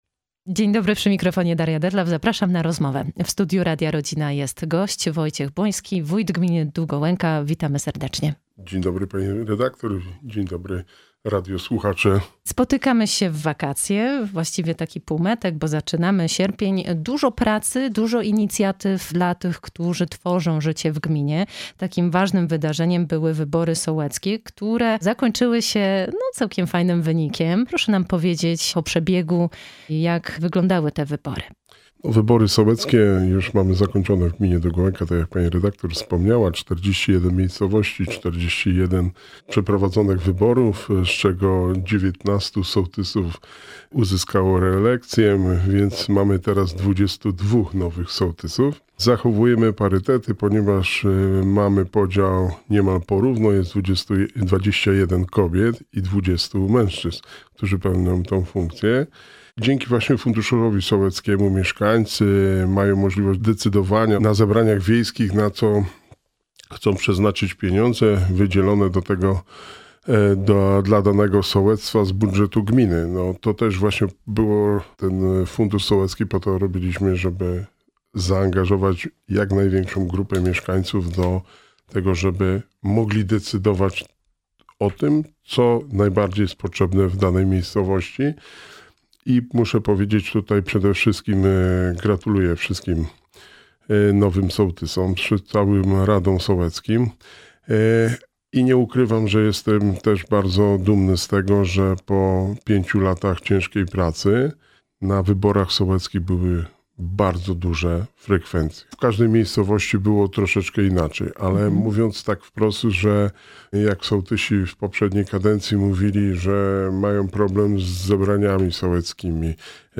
Wojciech Błoński, wójt Gminy Długołęka
Naszym gościem był Wójt Gminy Długołęka – Wojciech Błoński. Podsumował zakończone w lipcu wybory sołeckie w gminie.